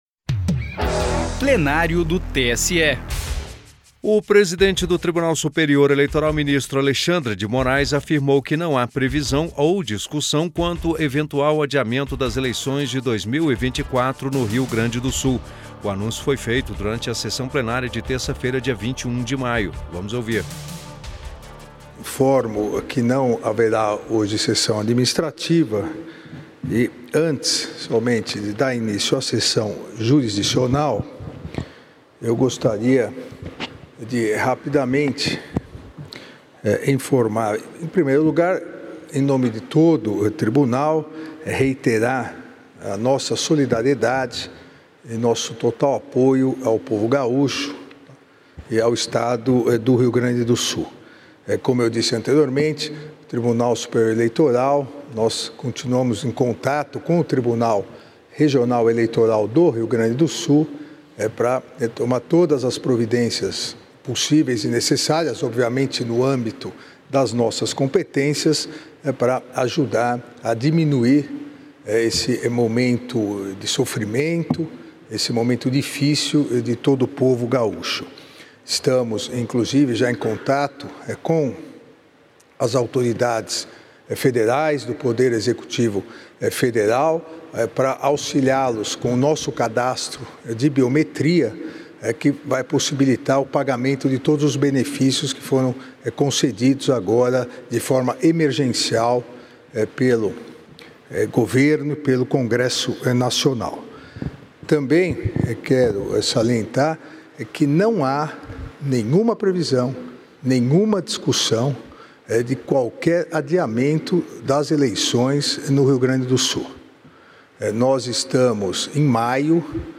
Declaração foi dada pelo ministro Alexandre de Moraes durante sessão plenária .